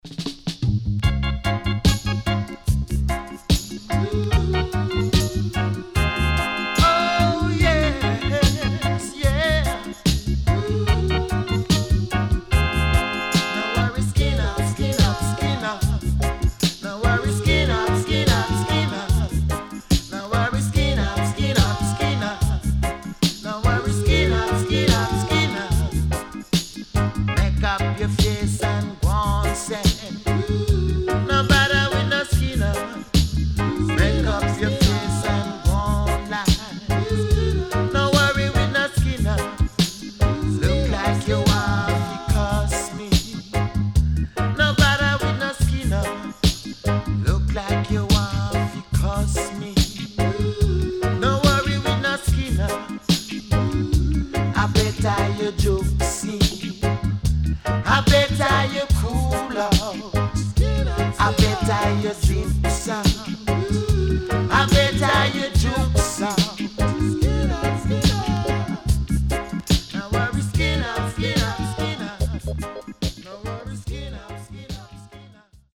HOME > Back Order [VINTAGE DISCO45]  >  INST 70's
SIDE B:少しチリノイズ入りますが良好です。